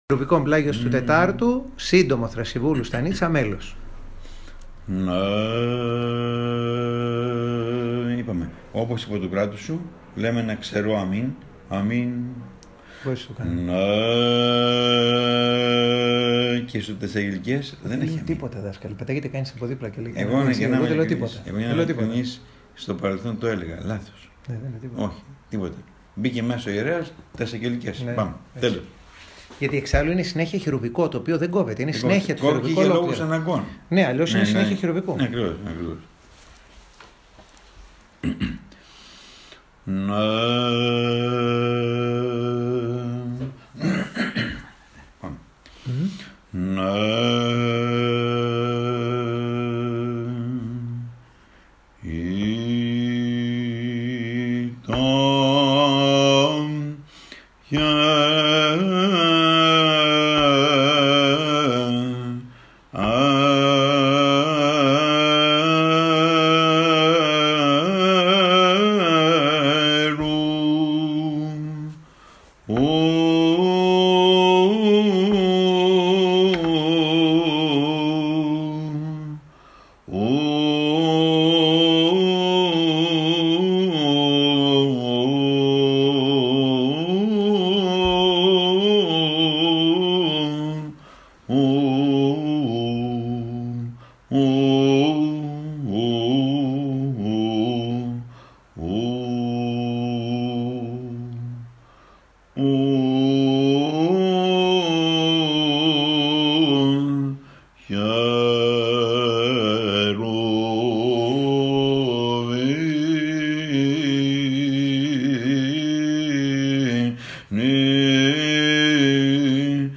ἦχος πλ. δ΄